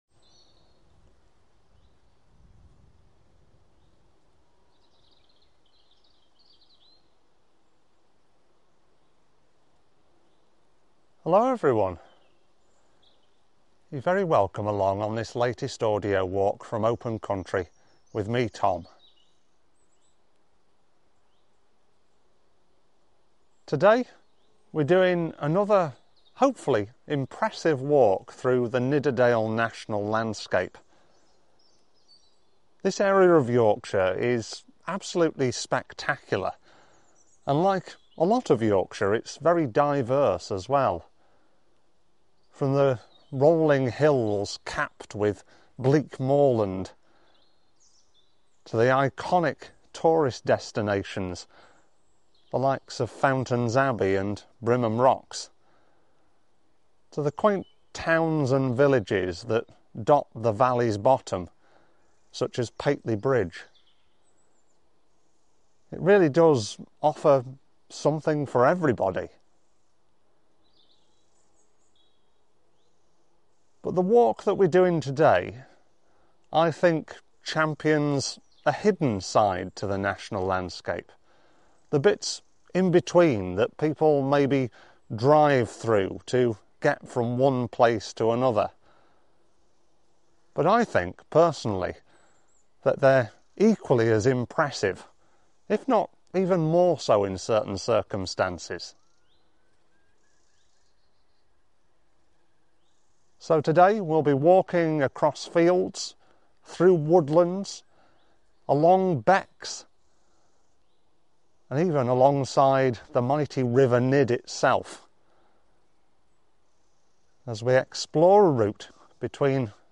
Thornthwaite and Darley Audio Walk